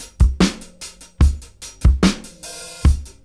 73-DRY-05.wav